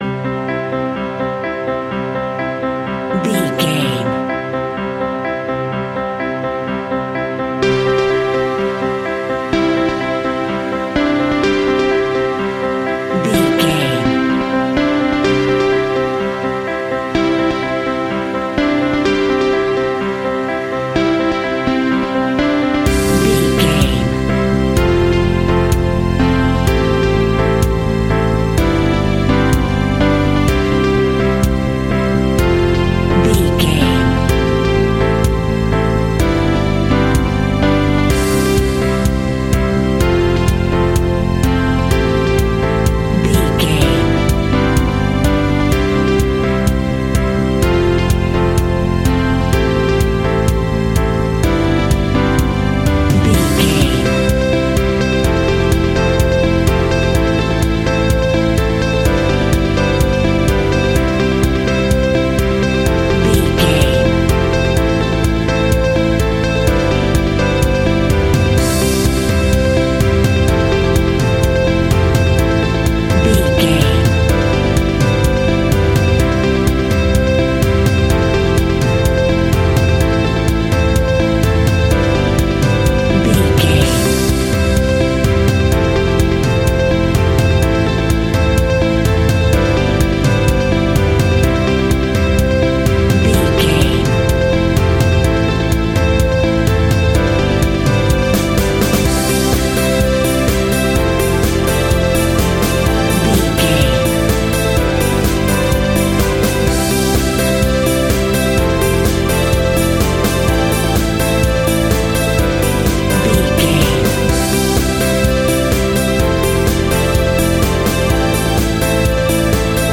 Ionian/Major
melancholic
smooth
uplifting
piano
electric guitar
bass guitar
drums
pop rock
indie pop
instrumentals
organ